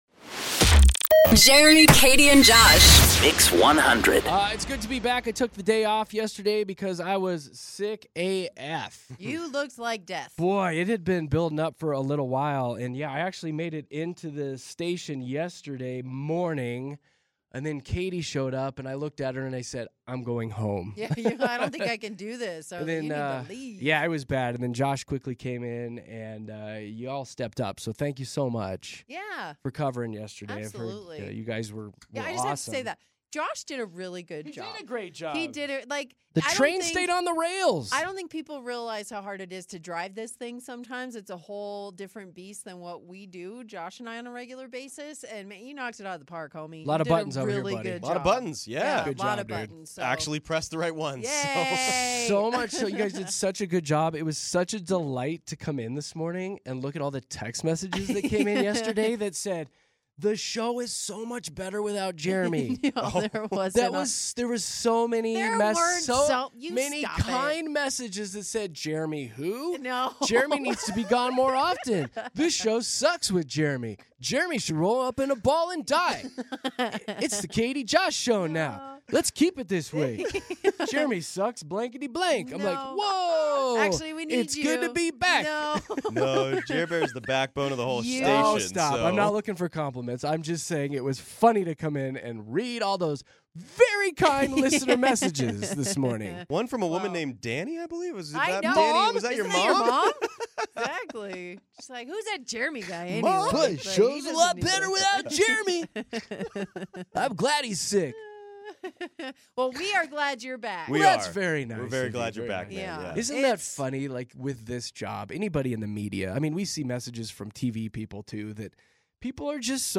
We discuss some of the most memorable and cringe-worthy proposals we've encountered, and you call in to share your touching stories that leave us in tears! We truly appreciate the wonderful (and often funny) tales of your proposals!